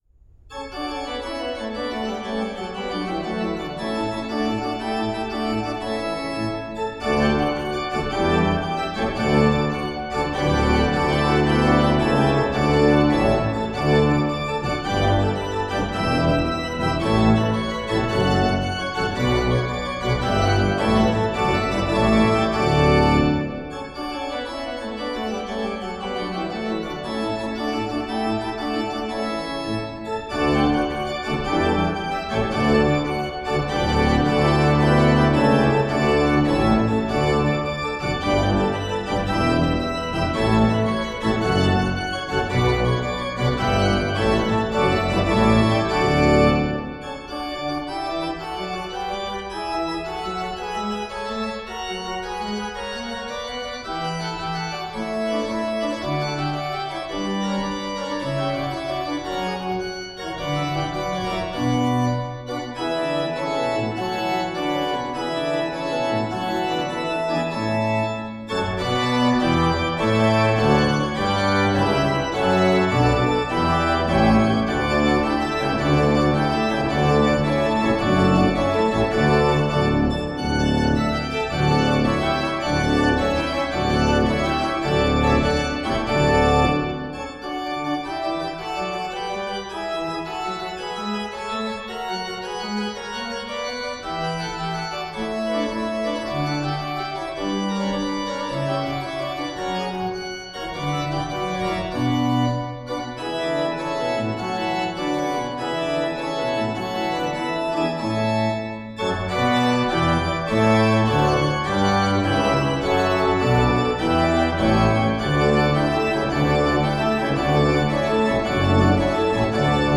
Organ of St. Pankratius-Kirche Ochsenwerder
Vivace
Orgel